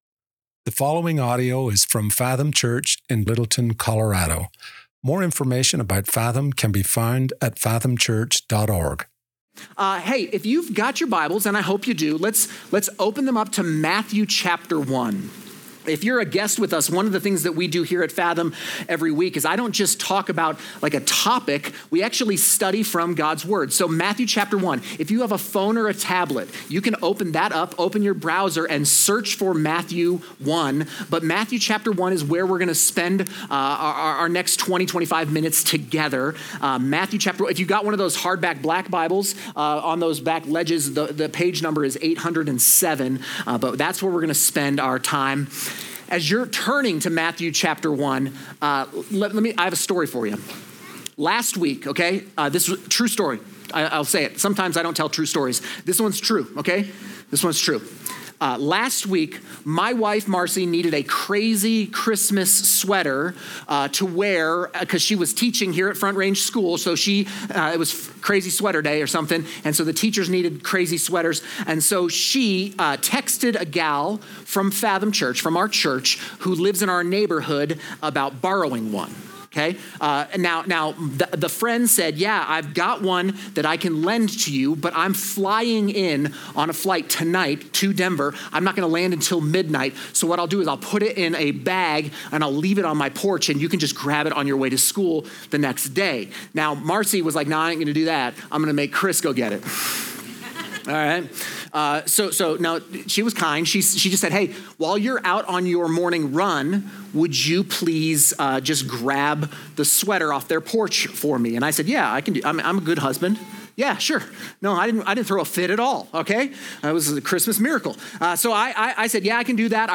ABOUT THIS SERMON SERIES: Out of our 16 or so waking hours each day, how many of those hours do you imagine we spend thinking about things in the future or the past?